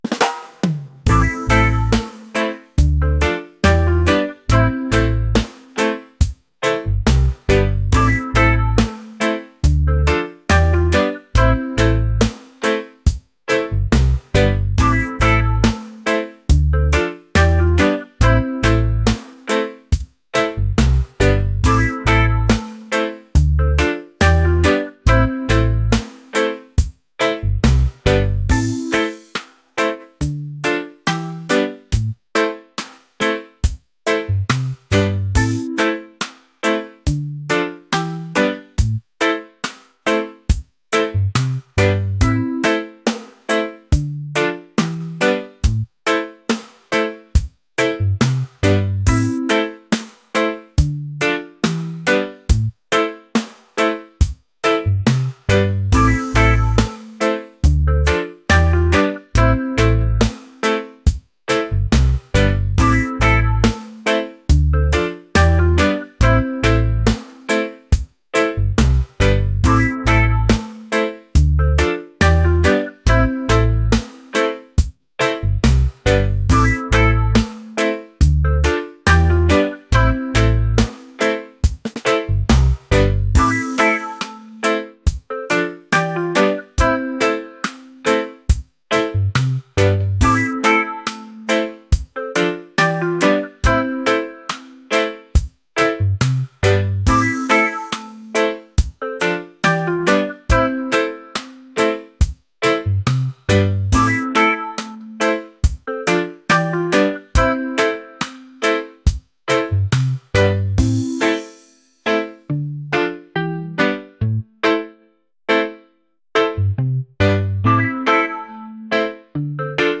laid-back | reggae | romantic